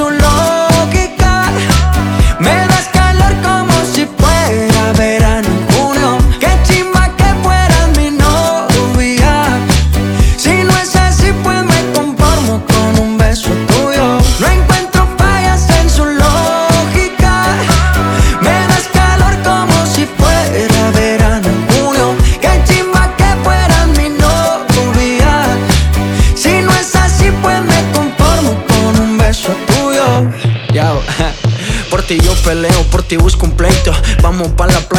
Жанр: Латиноамериканская музыка
# Urbano latino